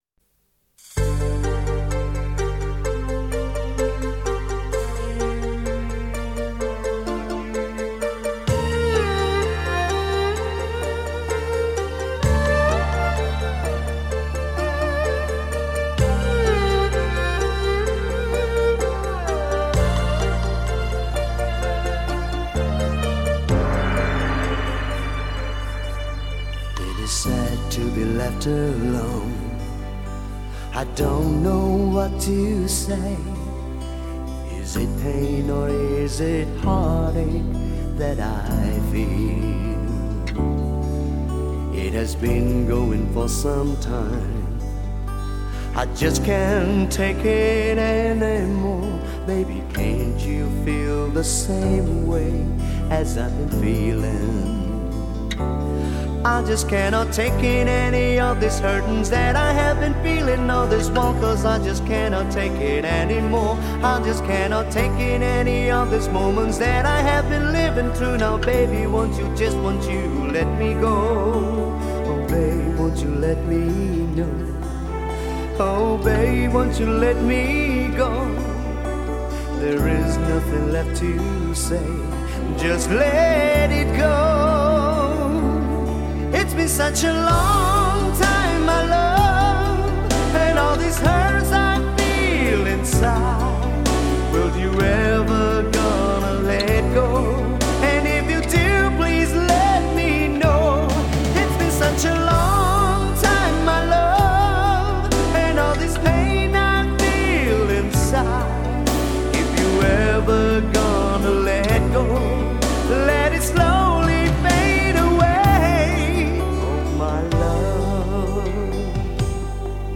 至情至圣 现代情歌新经典